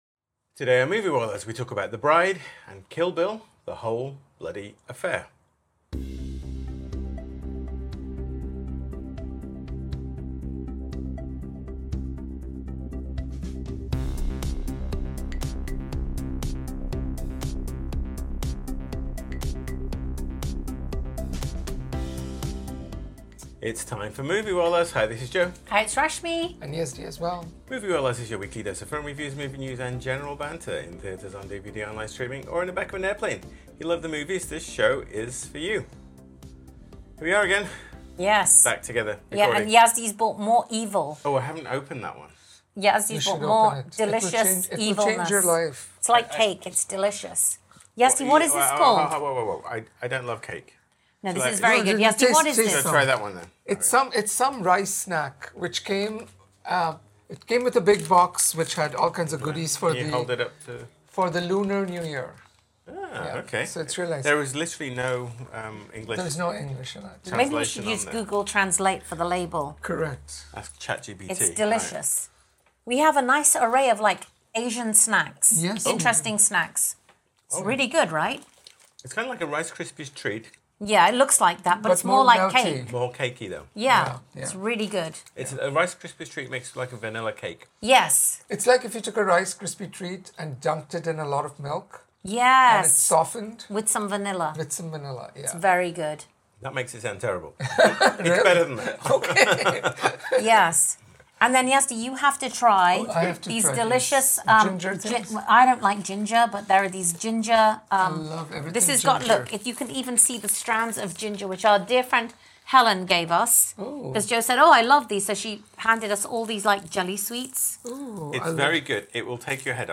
Welcome back to Moviewallas, your weekly dose of film reviews, movie news, and general banter – in theatres, on streaming, or in the back of an airplane.